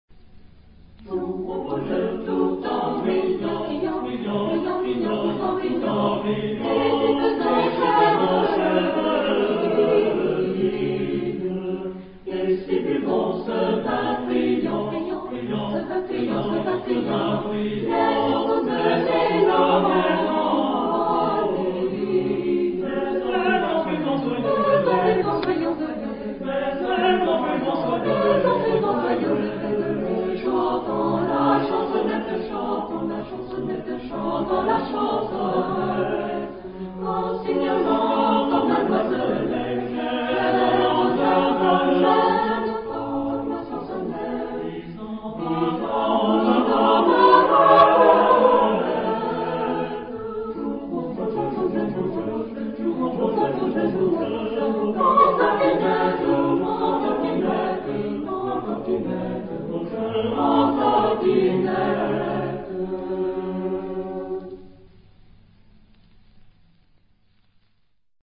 Epoque: 16th century
Genre-Style-Form: Renaissance ; Secular
Type of Choir: SATB  (4 mixed voices )